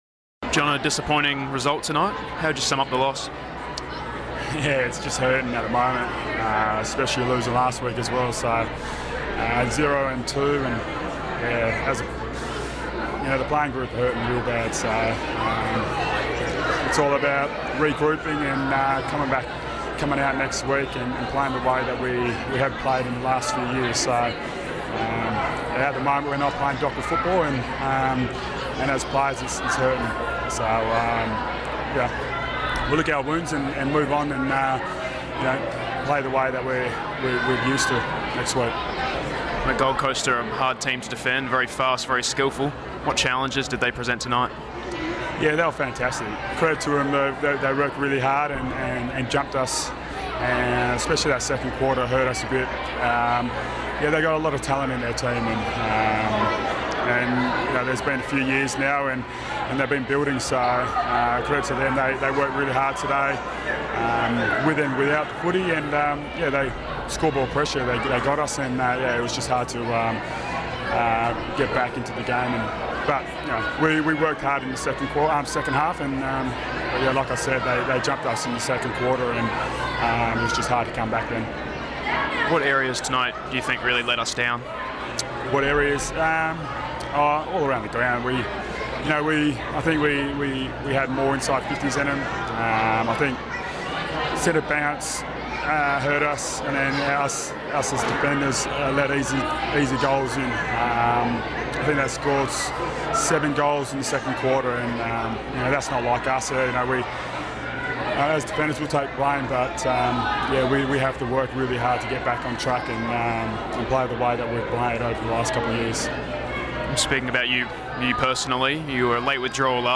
Michael Johnson post match - Round 2 v Suns